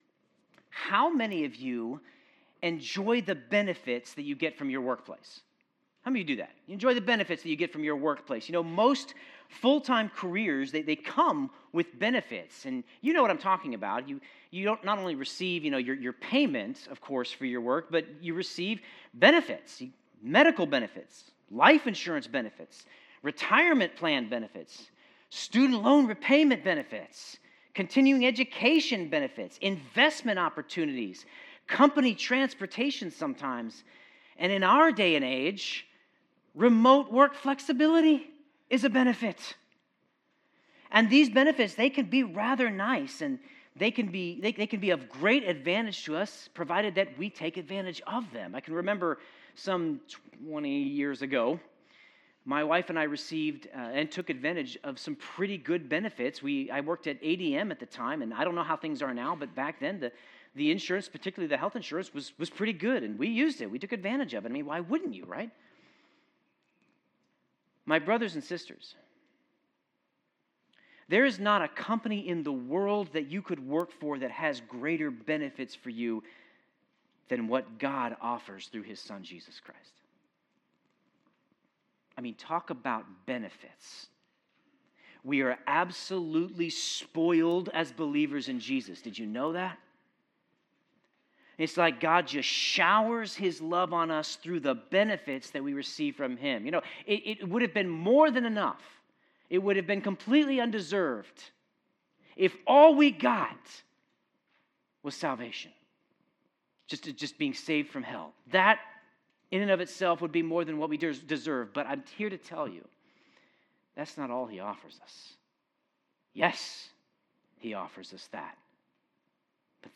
Sermon Notes God showers His love on us through the benefits we receive from Him.